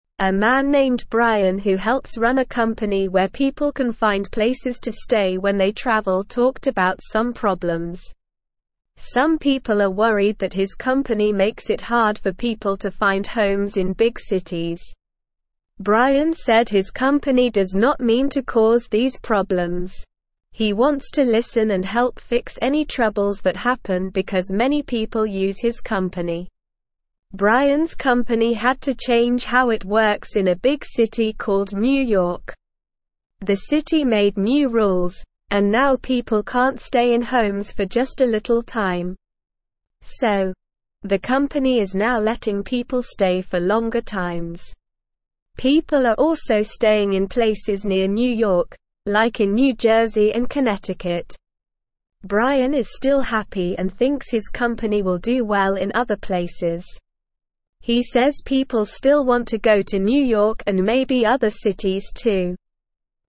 Slow
English-Newsroom-Beginner-SLOW-Reading-Brian-Helps-People-Find-Places-to-Stay-When-They-Travel.mp3